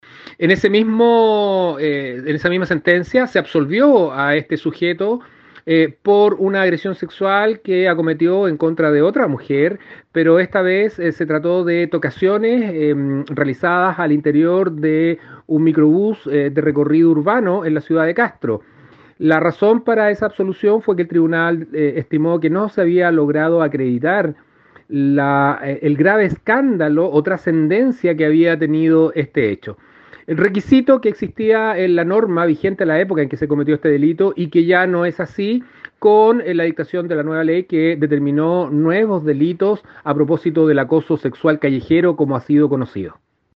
Respecto de la segunda causa, de la cual este individuo fue absuelto, el representante del ministerio Público explicó cuáles fueron los elementos que pesaron para llegar a esa decisión.